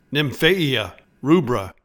Pronounciation:
Nim-FAA-e-a RUE-bra